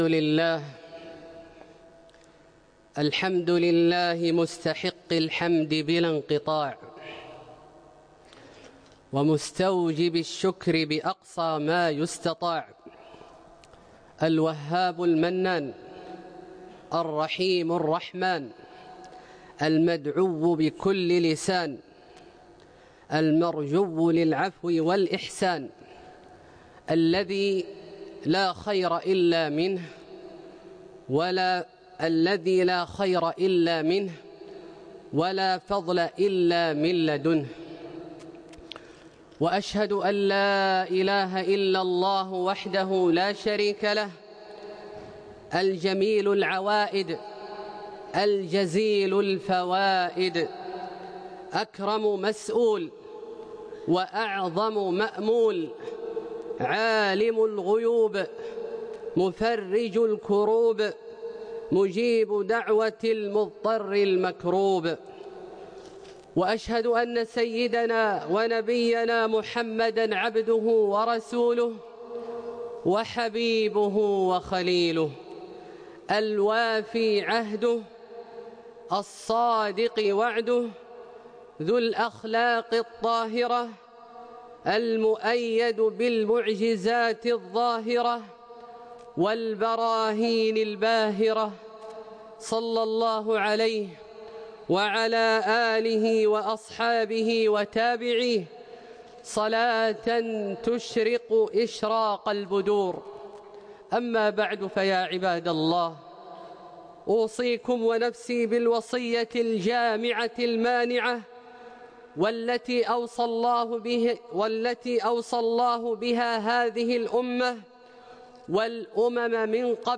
خطبة - خيركم من تعلم القرآن وعلمه